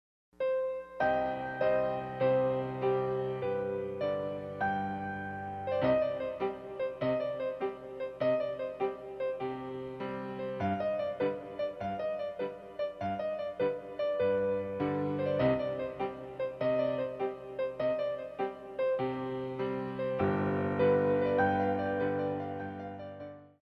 46 Piano Selections.